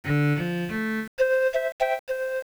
Simply, two earcons are concatenated to produce a new, more complex earcon.
Figure 1: Open HyperCard compound earcon
The two component earcons have a 0.1 second gap between them to help listeners tell where the first finishes and the second starts, otherwise the sounds are the same as those from the previous page.